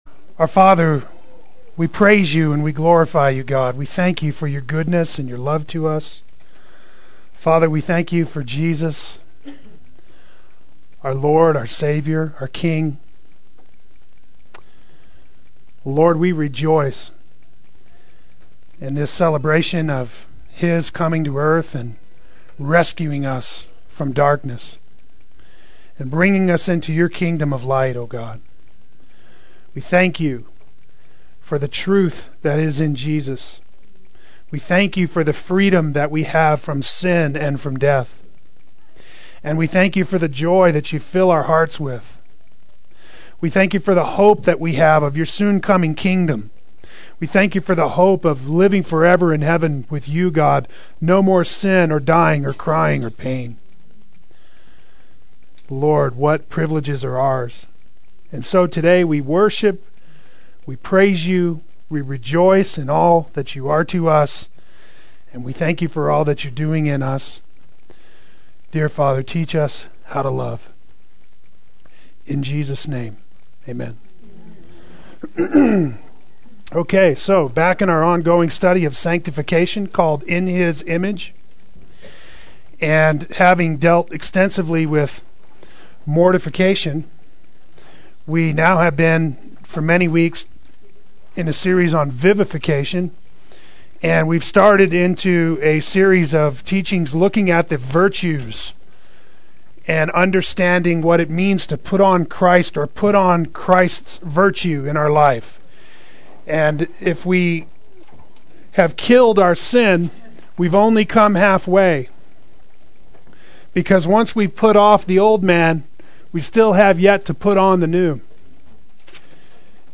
Part 4 Adult Sunday School